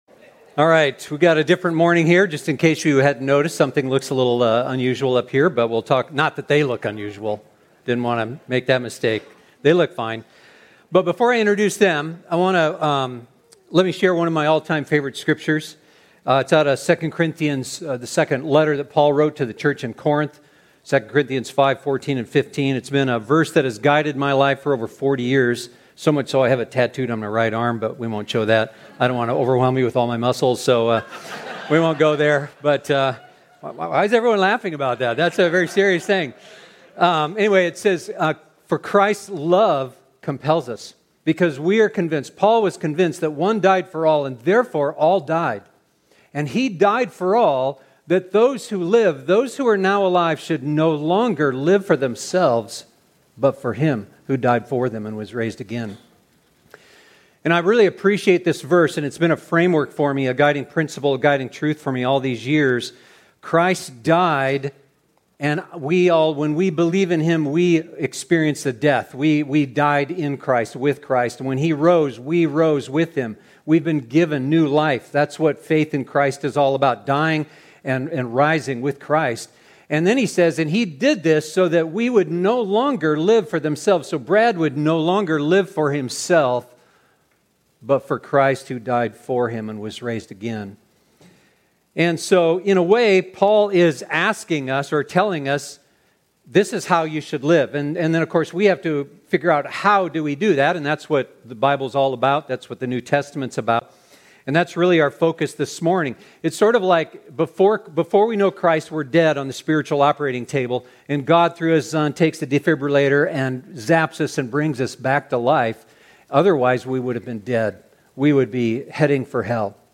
The Devotional Life Panel Discussion